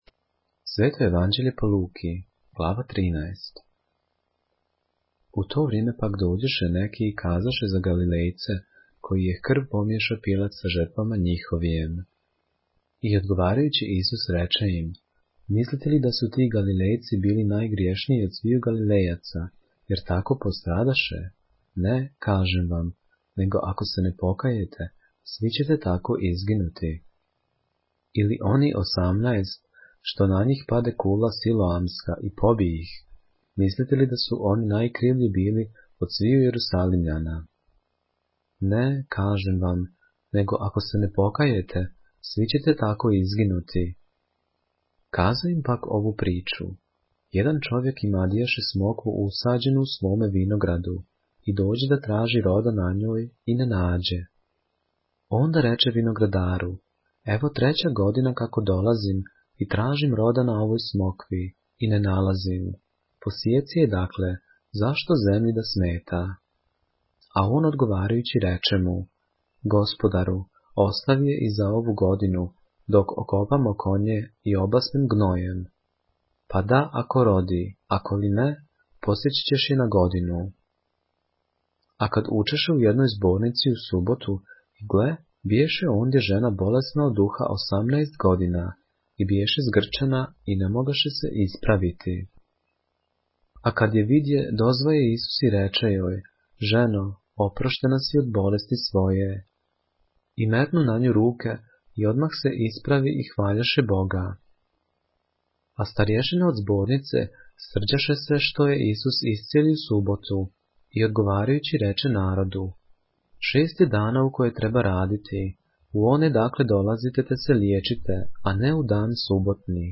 поглавље српске Библије - са аудио нарације - Luke, chapter 13 of the Holy Bible in the Serbian language